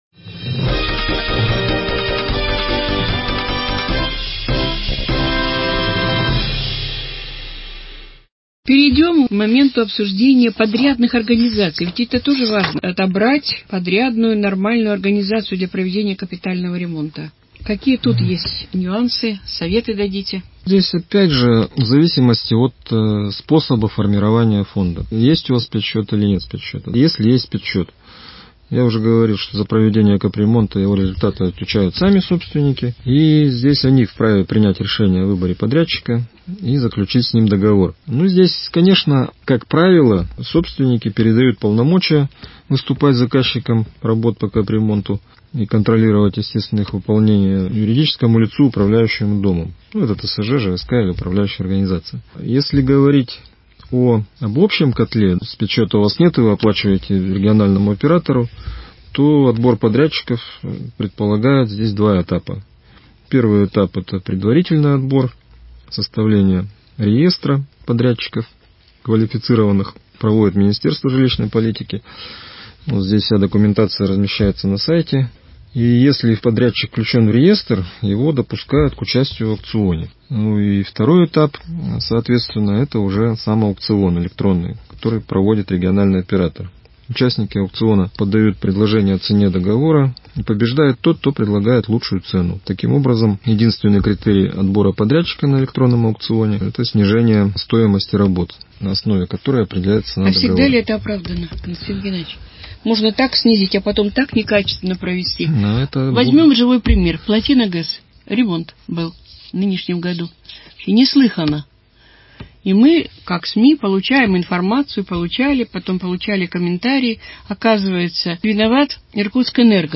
Формат передачи «Вопрос – ответ».